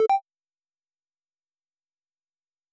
menu_select.wav